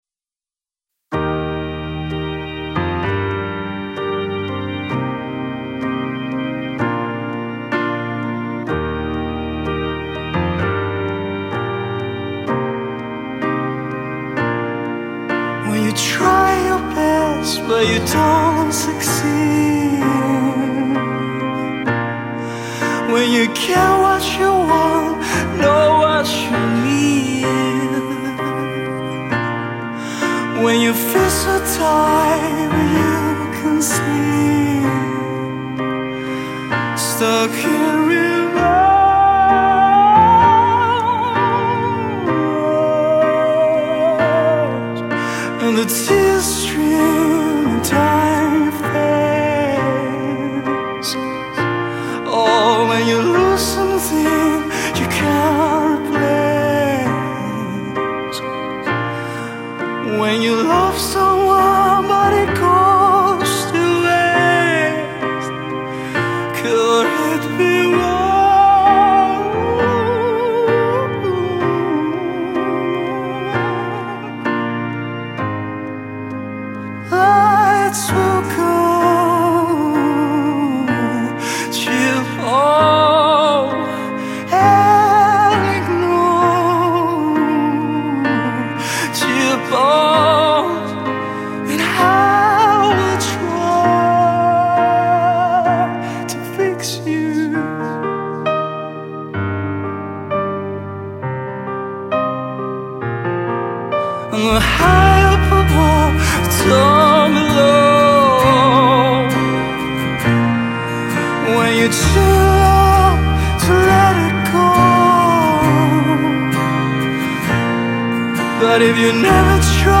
Rock live.